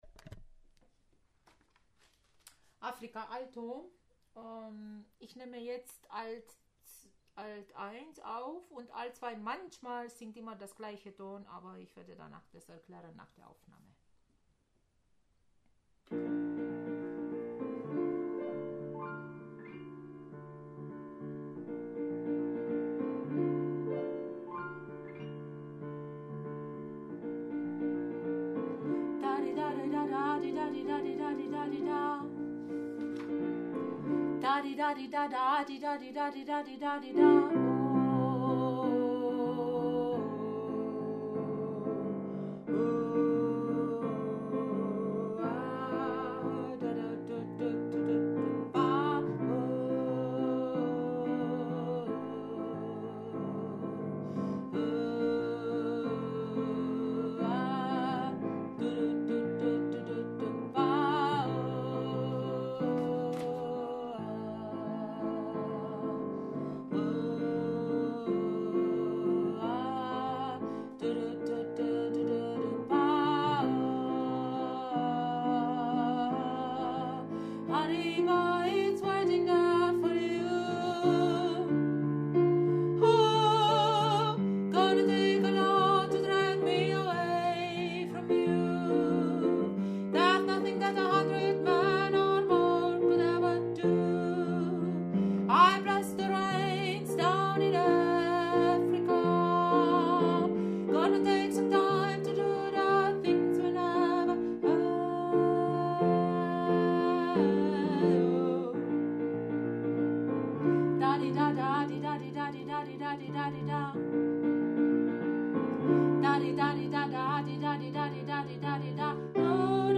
Africa – Alto